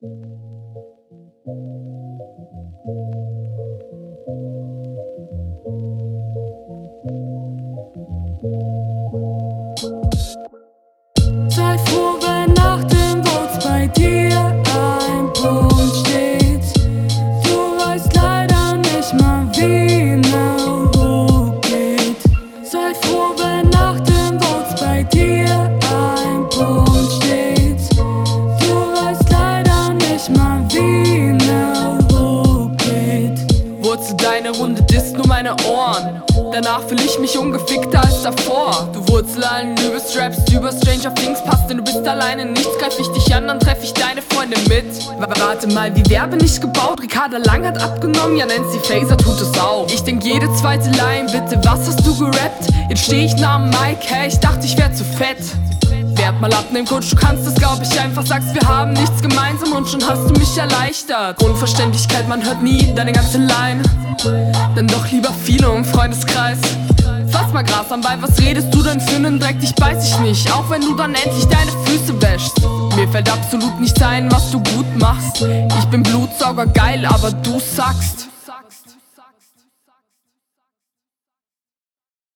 Sehr gut gekontert hier ist alles stimmiger, die Hook ist Geschmackssache aber singen kannste auch.
Die Hook ist nicht meins, aber besser als die des Gegners weil musikalisch richtig.
Flow: Hook kommt gut, für mich flowst du sogar noch ein Stück besser als in …
Raptechnisch wieder sehr stark und vor allem auch echt cool gerappt.